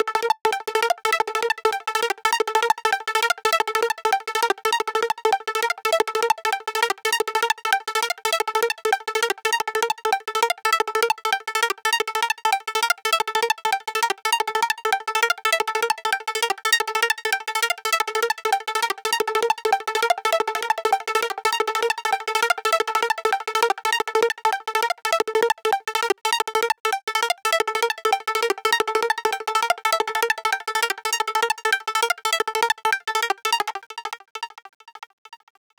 Dreamquest-A-200Bpm-Arp.wav